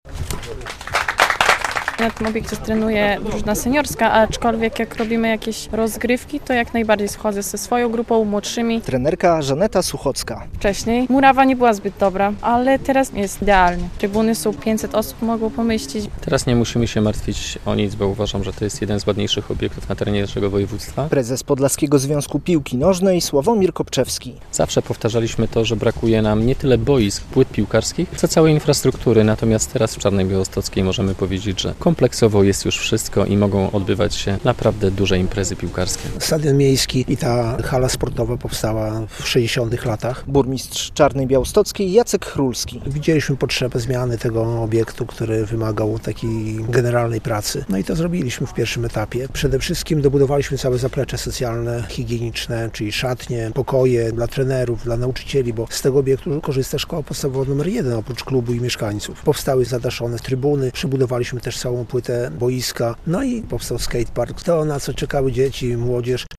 Nowoczesne zaplecze dla szkoły i klubu - stadion w Czarnej Białostockiej już otwarty - relacja
Jak mówi burmistrz Czarnej Białostockiej Jacek Chrulski - to długo wyczekiwana inwestycja.